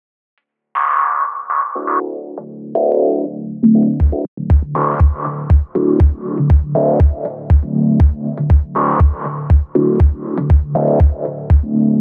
помогите нарулить бас/лид
Точно трудно попасть, такое чувство что-то роландовское.